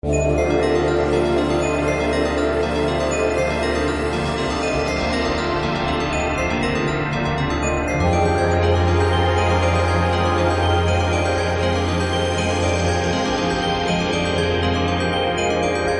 鹦鹉
描述：两声来自未知鹦鹉的叫声。用Zoom H2录制的。
标签： 充满异国情调 现场录音 鹦鹉 雨林 热带 动物园
声道立体声